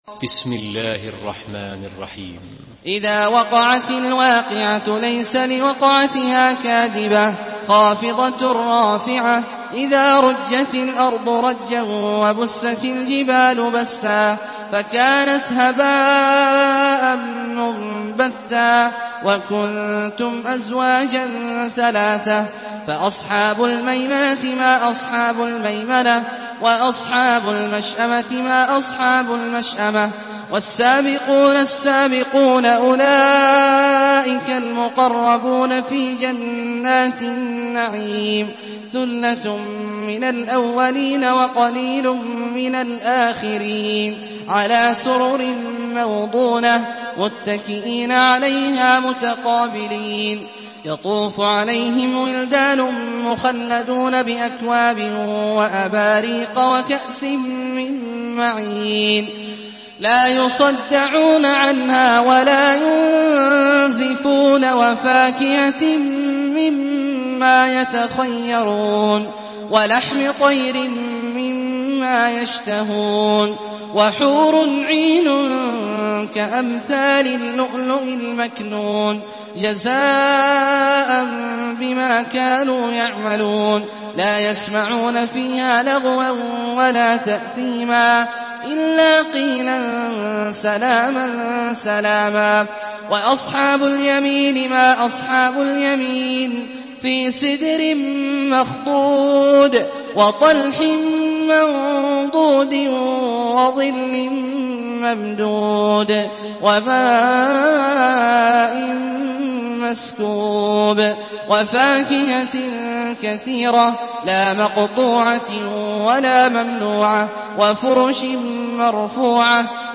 Surah আল-ওয়াক্বি‘আহ MP3 by Abdullah Al-Juhani in Hafs An Asim narration.
Murattal